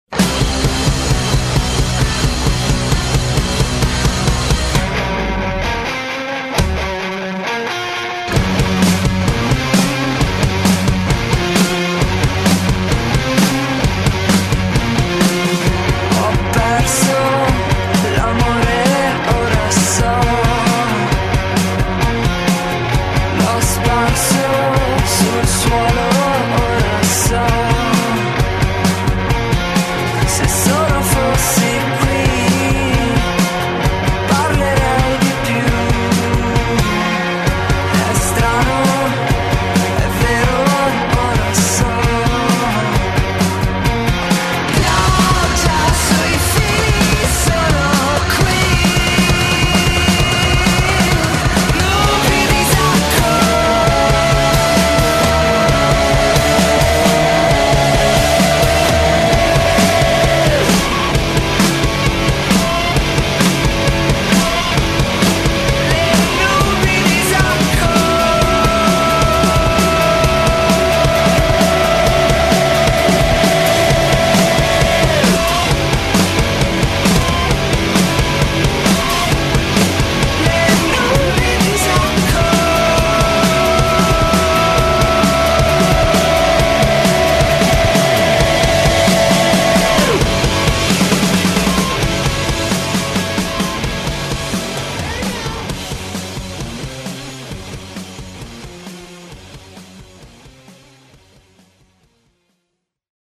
oscurità nella musica
tornano in tre